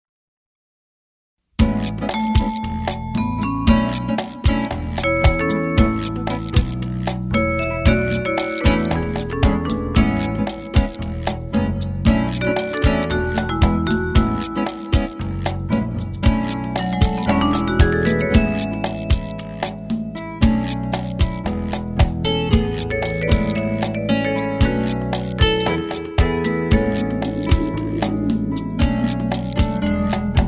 Instrumental Tracks.